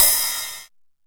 TR 909 Cymbal 04.wav